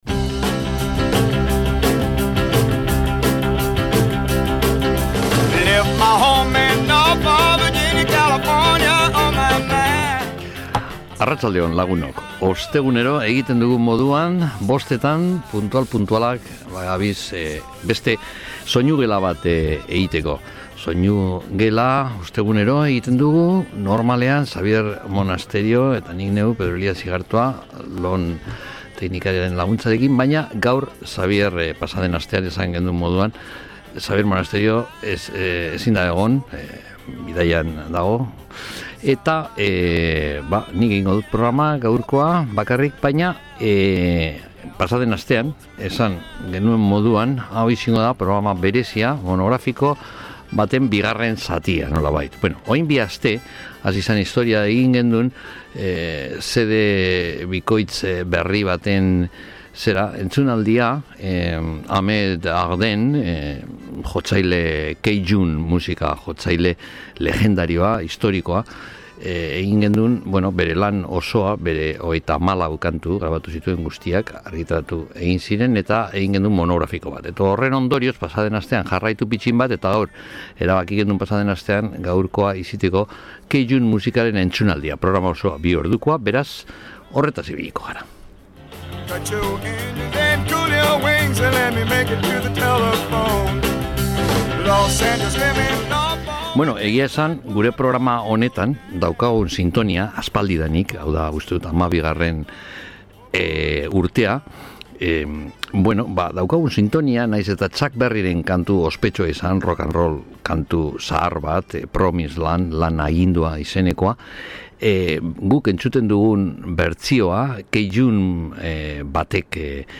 Bi orduko monografikoa izan dogu azken Soinugelan.